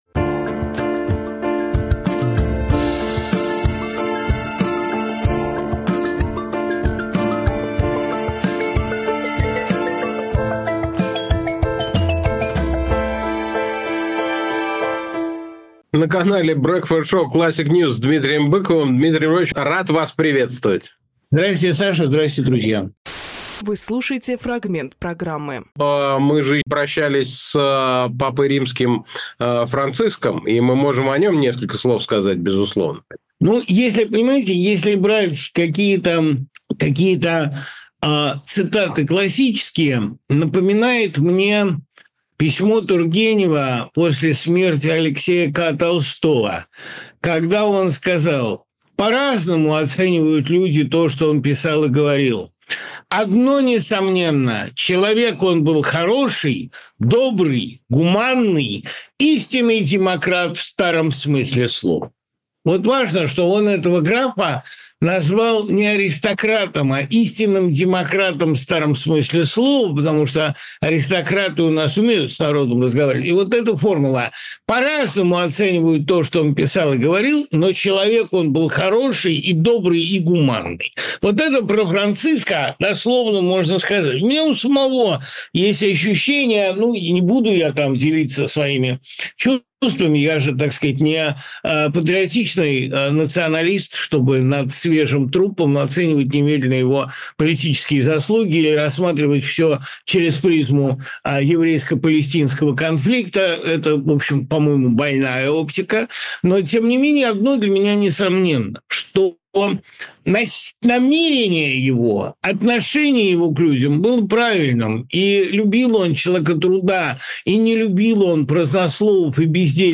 Фрагмент эфира от 27.04.25